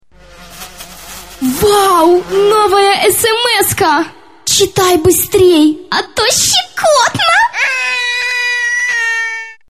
Категория: SMS-мелодии